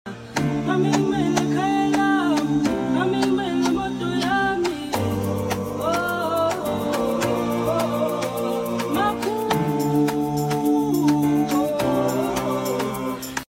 ocean waves 🌊🤙🏽🏄🏽‍♂🌞 sound effects free download